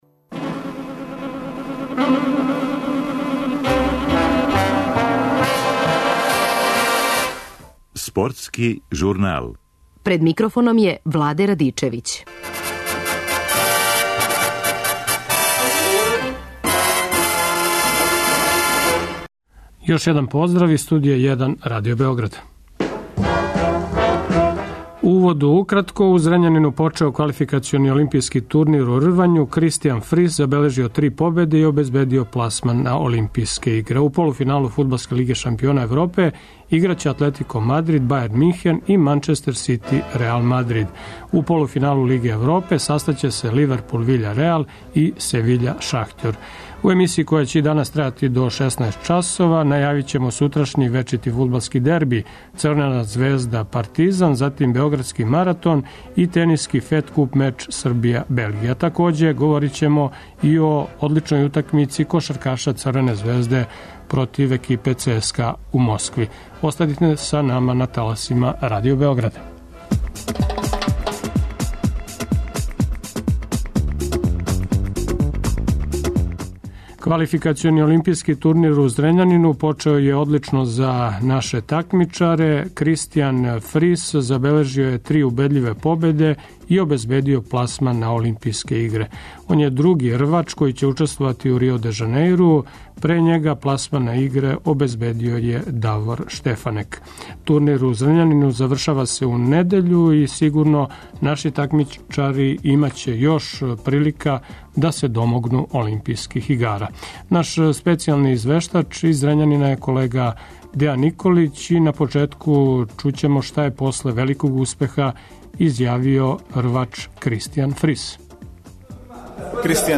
Пред 29. Београдски маратон чућемо укључење нашег репортера.